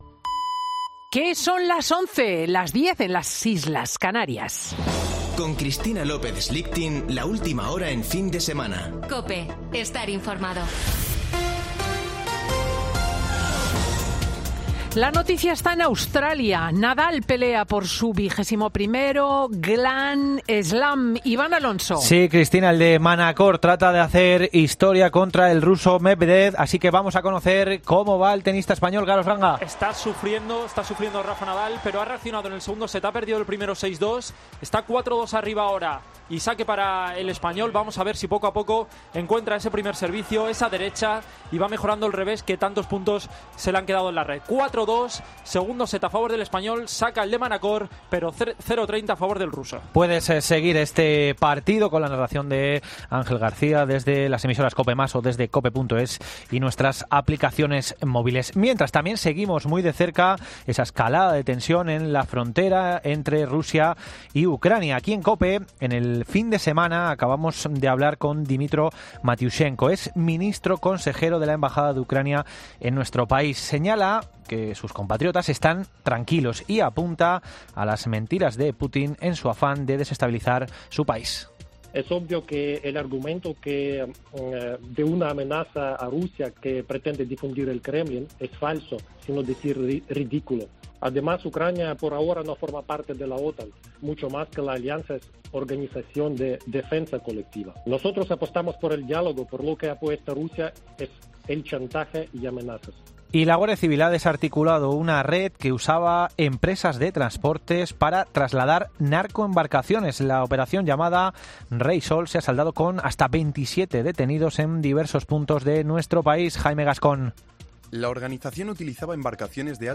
Boletín de noticias COPE del 30 de enero de 2022 a las 11.00 horas